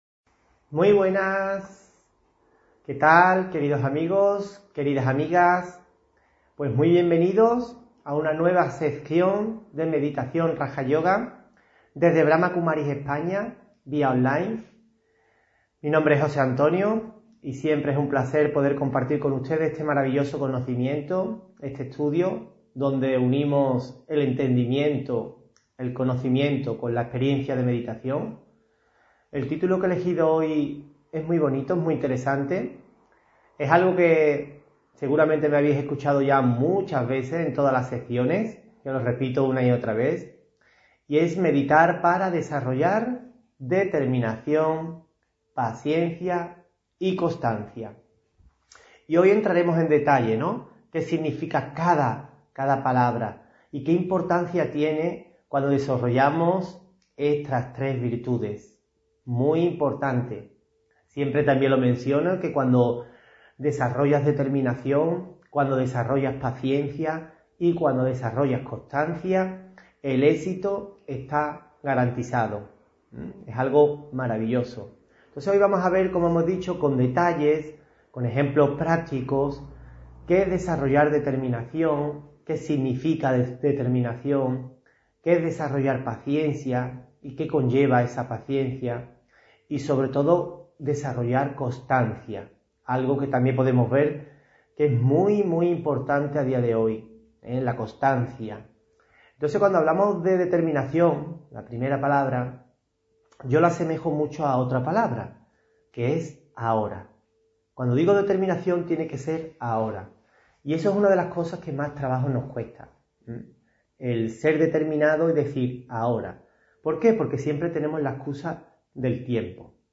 Meditación y conferencia: Meditar para desarrollar determinación, paciencia y constancia (1 Febrero 2023)